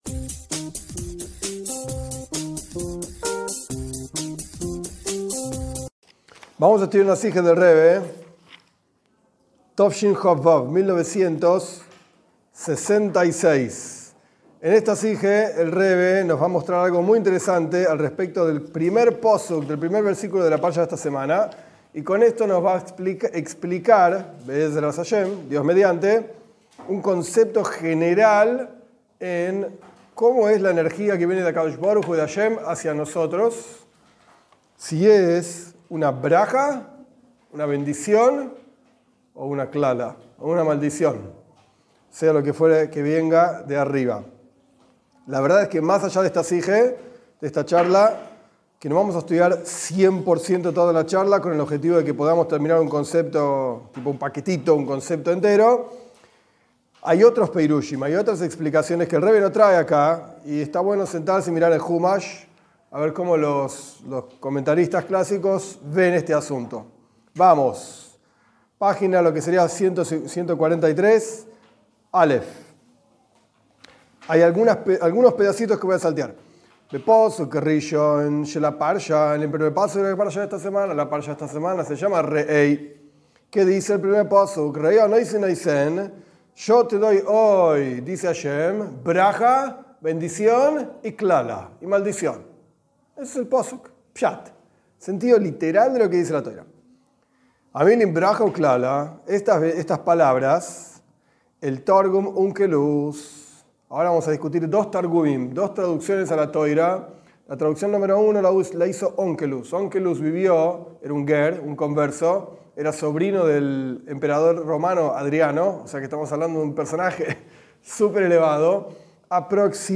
Esta es una clase basada en una charla del Rebe sobre el comienzo de parashat Reé.